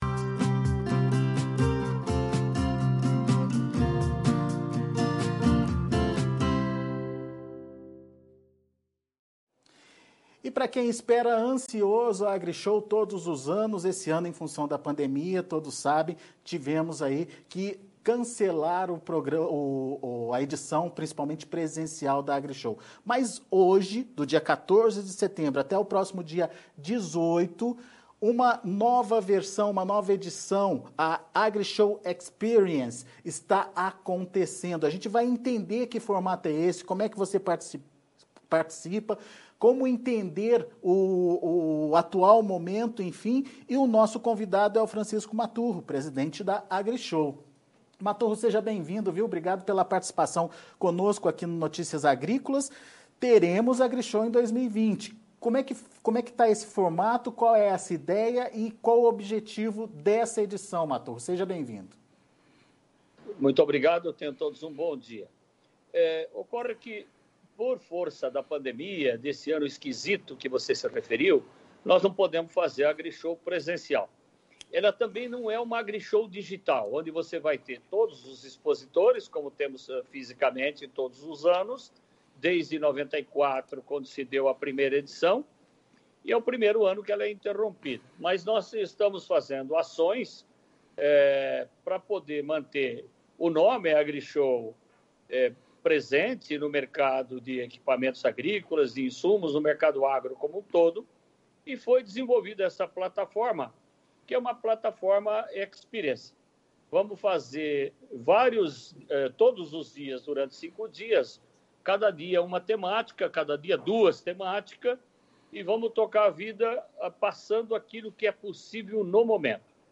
Entrevista com